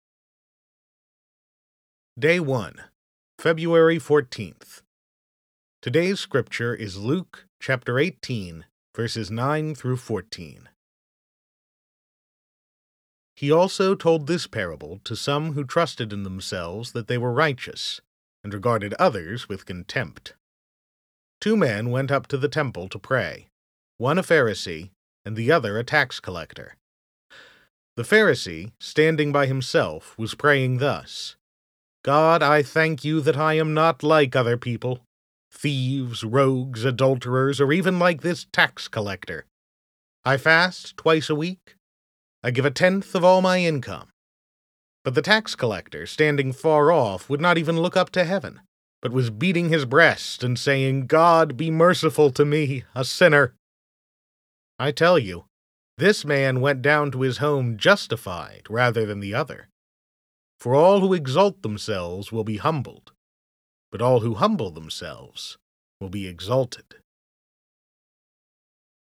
Scripture Audio | Devotional Audio